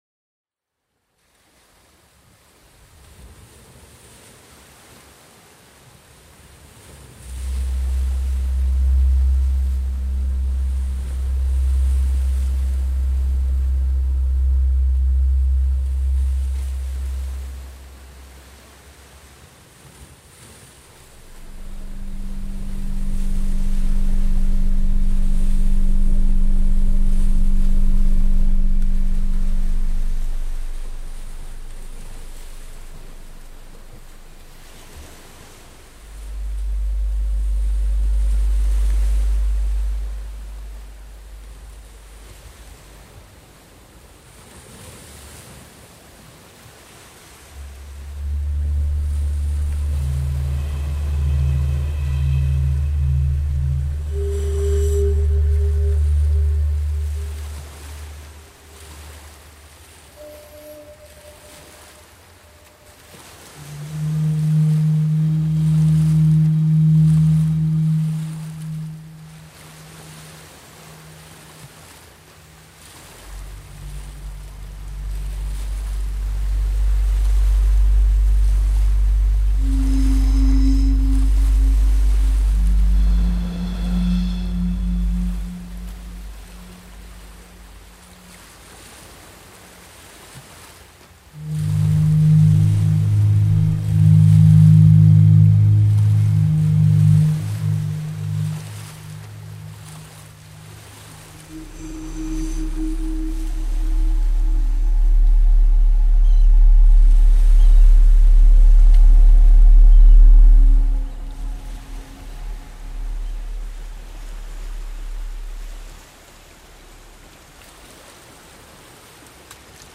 Virga est une composition électroacoustique pour: des enregistrements de sons de la nature, bruits, synthétiseurs, réverbération et des effets numériques.
Composition de musique électroacoustique pour : des enregistrements de sons de la nature quantifiés (orages, pluie et dégringolades de rochers), quelques bruits d'instruments plus succincts qu'il n'est point utile de reconnaître, deux synthétiseurs employés comme filtres, une réverbération à convolution, un délais et d'autres traitements numériques traitant le timbre et la dynamique.